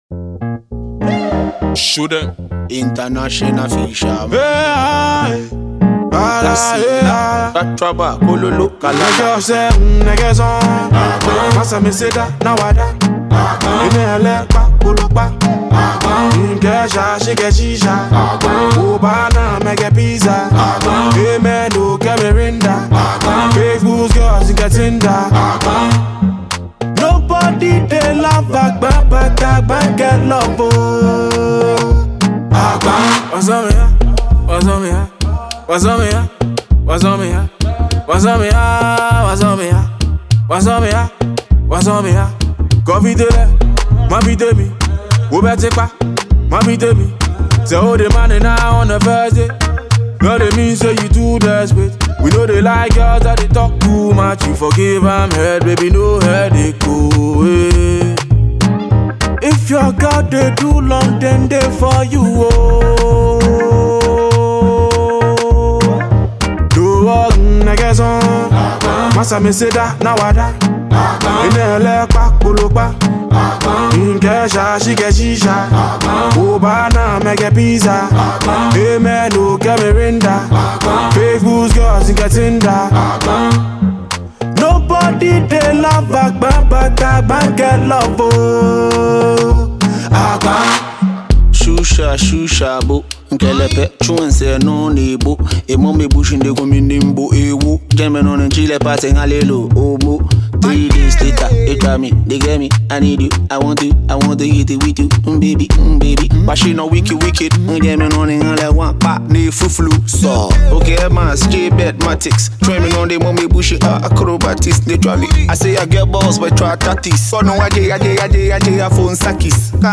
a Ghanaian Ga singer